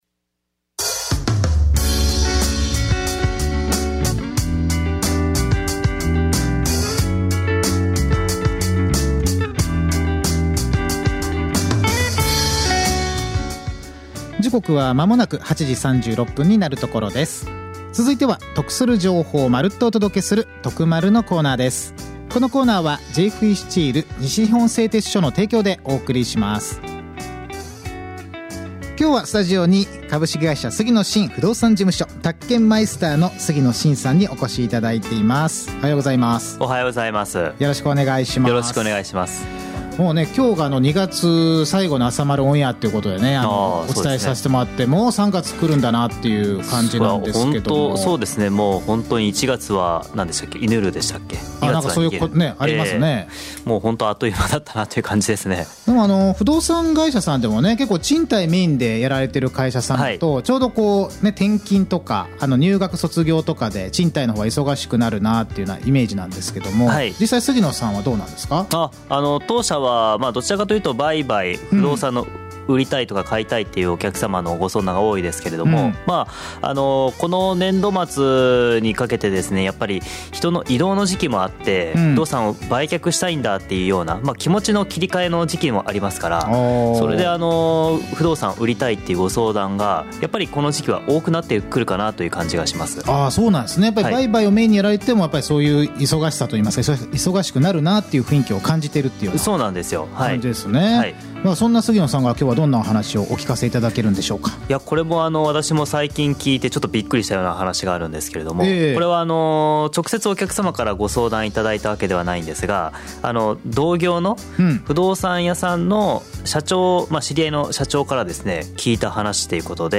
本日、FMふくやま（77.7MHz）の朝の番組「あさまる」内のコーナーにゲスト出演させていただきました。